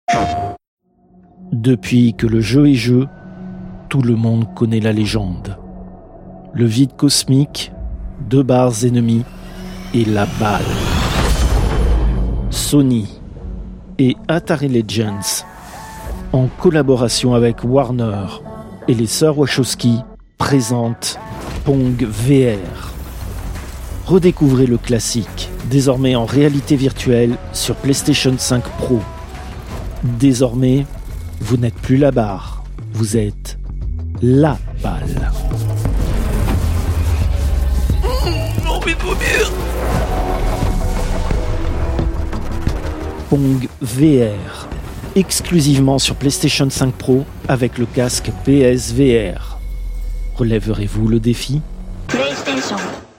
Fausse publicité : Pong VR